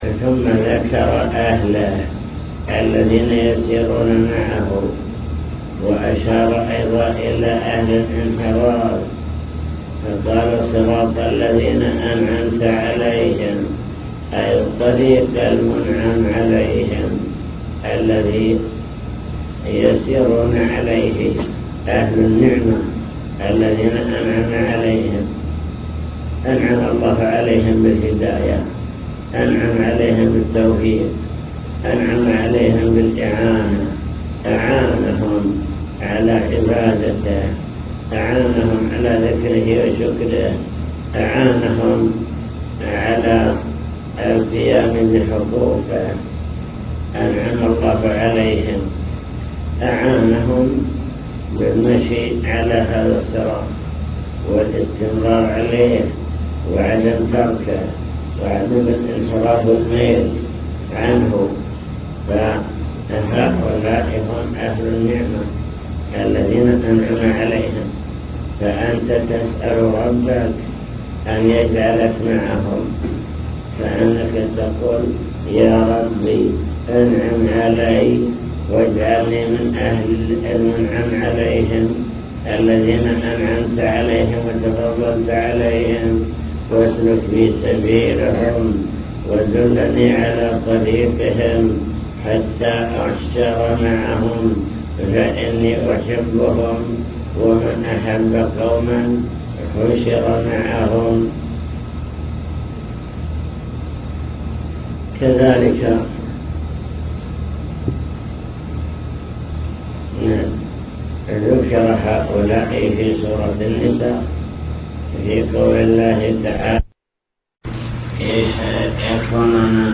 المكتبة الصوتية  تسجيلات - لقاءات  حول أركان الصلاة (لقاء مفتوح) من أركان الصلاة: قراءة الفاتحة